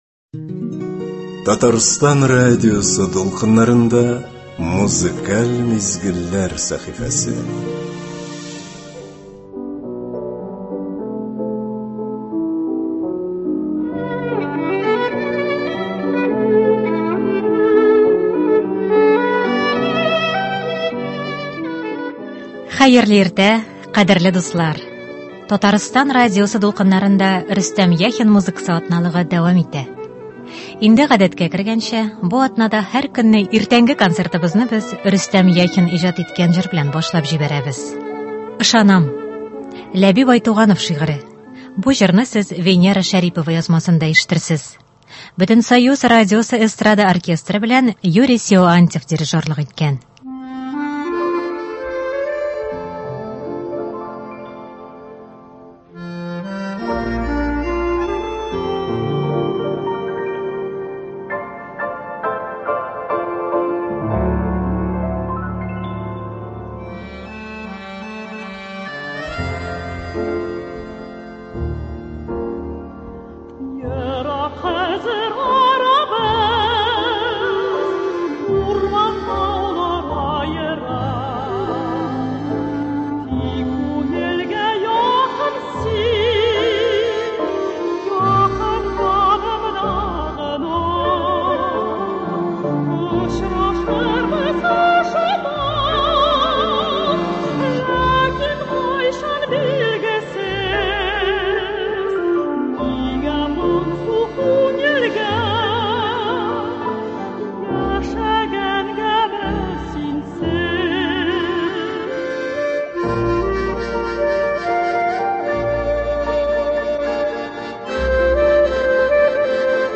Иртәнге концертта яраткан җырларыбыз яңгырый.